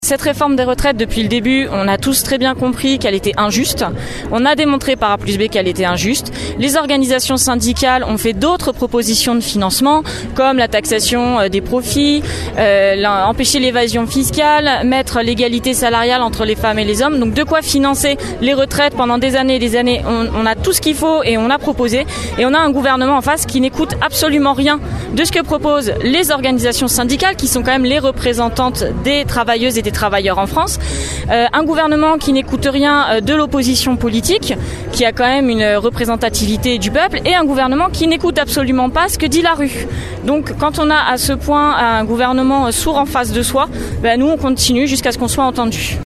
Hier matin, lors du rassemblement place Colbert à Rochefort.